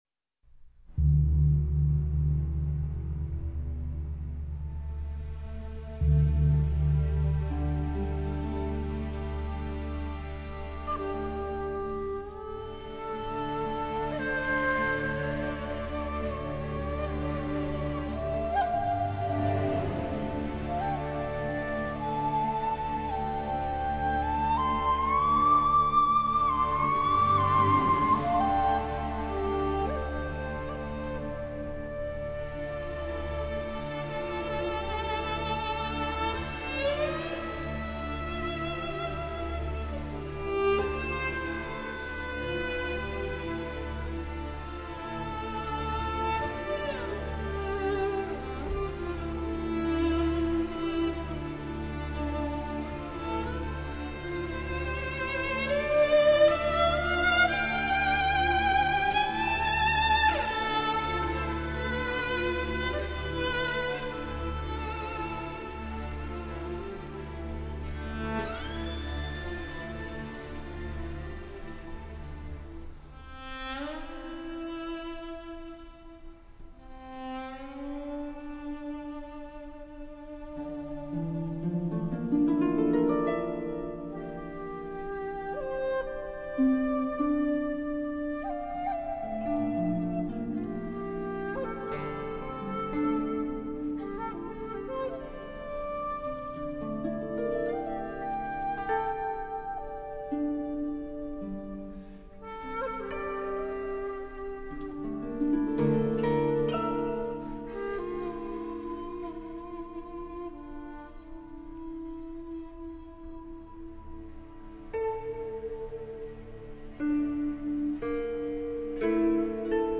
В том числе и благодаря красивой инструментальной музыке.